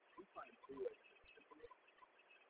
描述：重建信号5dB
Tag: SNR 5分贝 重构